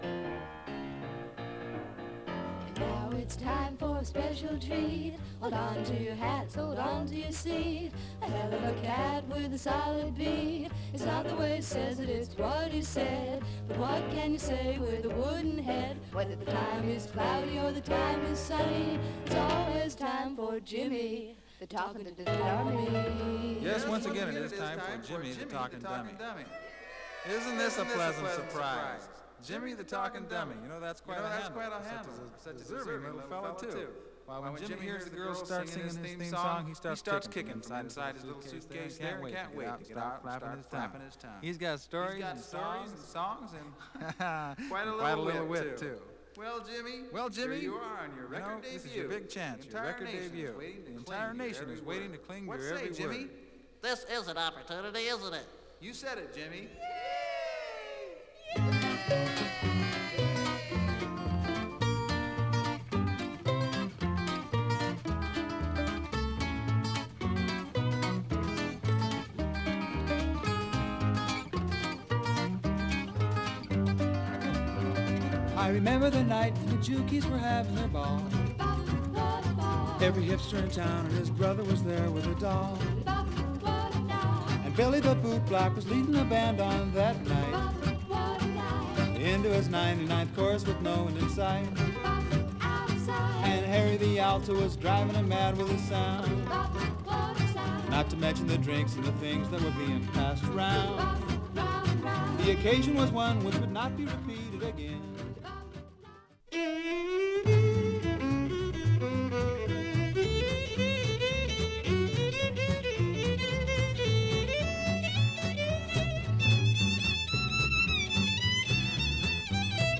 グッド・スイング〜ジャグ、フォーク、カントリーと良い意味でごった煮感が古き良きアメリカを象徴したような１枚。